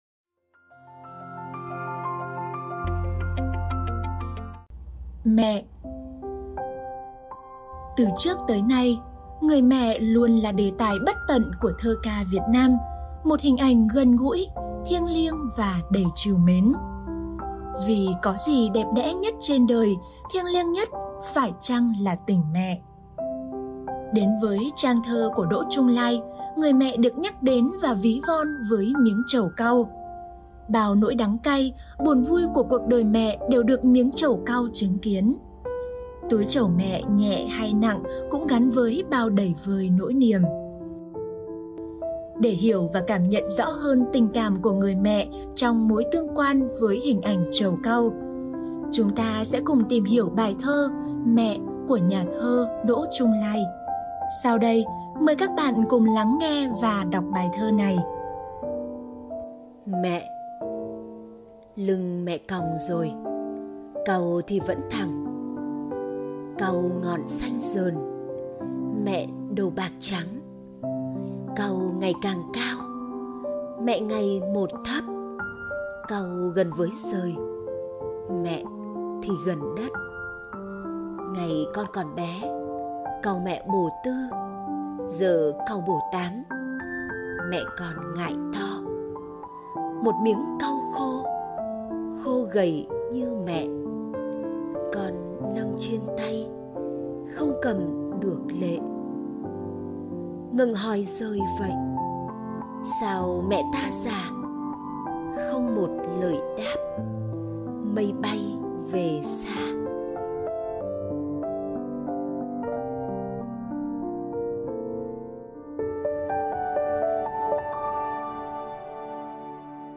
Sách nói | Mẹ - Ngữ văn 7